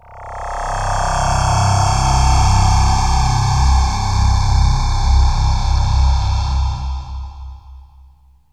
AMBIENT ATMOSPHERES-4 0004.wav